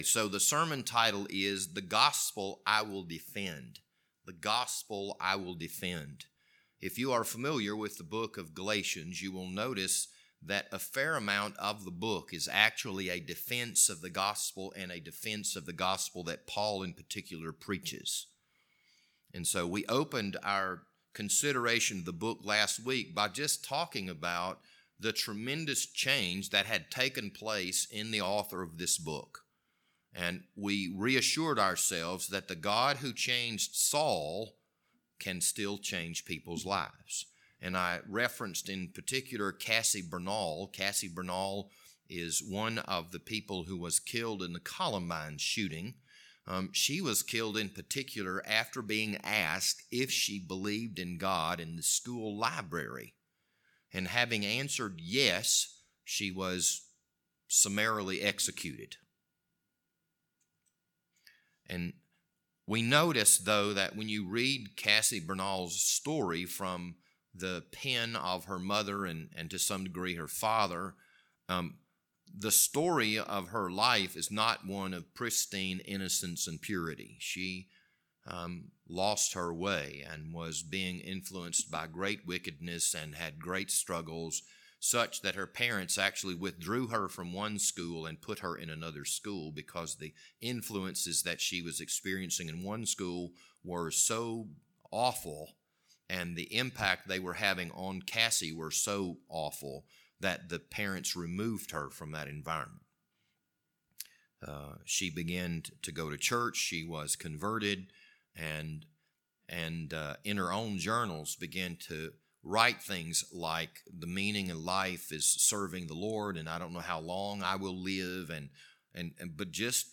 This Sunday evening sermon was recorded on March 6th, 2022.